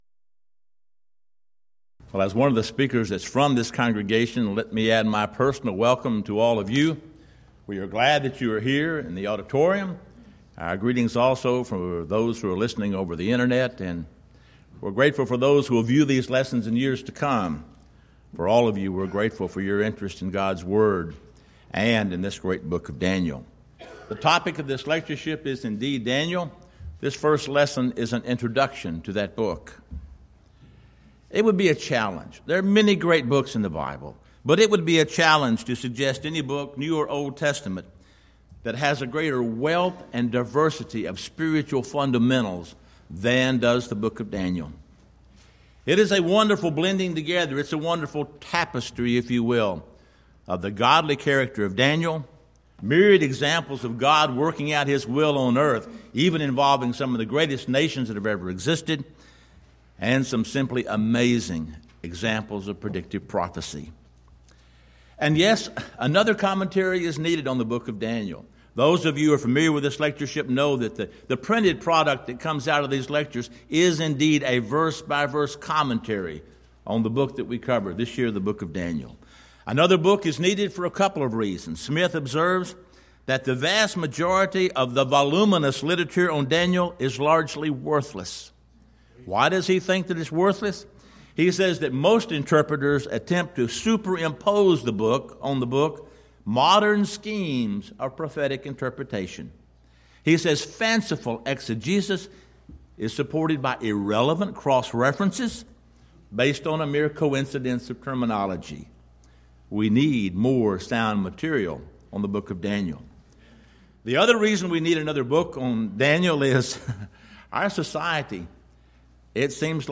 Event: 11th Annual Schertz Lectures
lecture